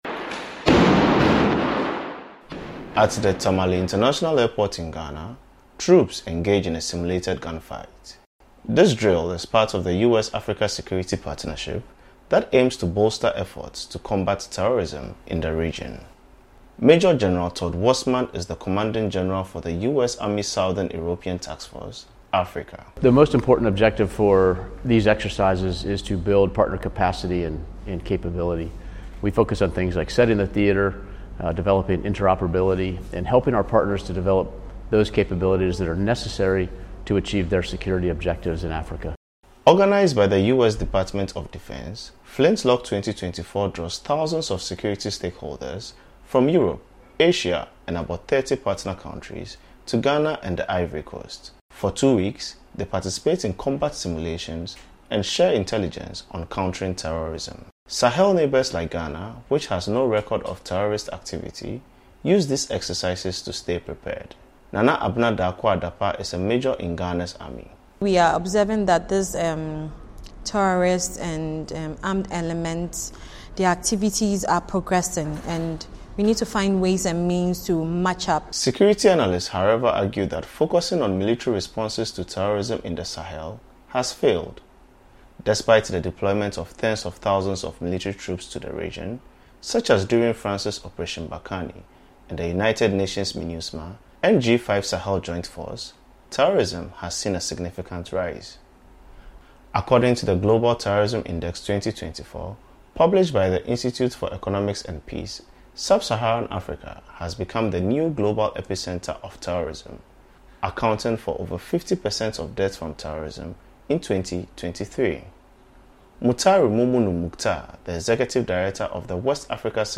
reports from Tamale, Ghana